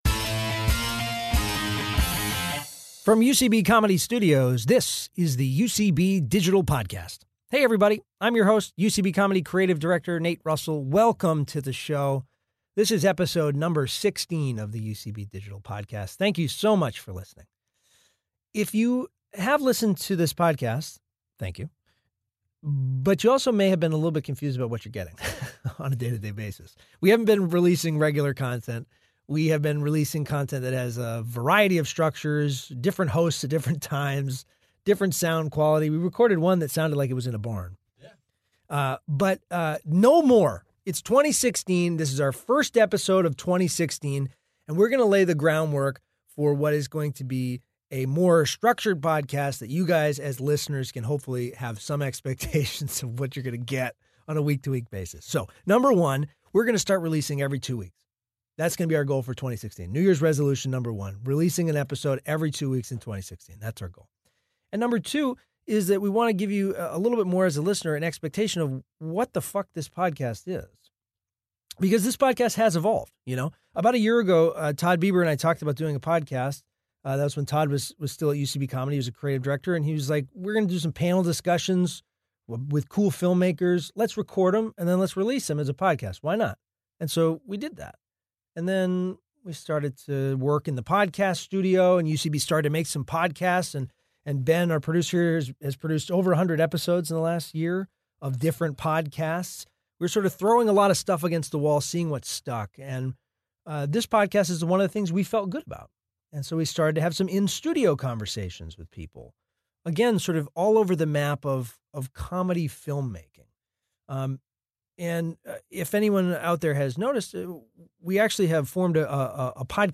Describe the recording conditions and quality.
Recorded at UCB Comedy Studio West in Los Angeles.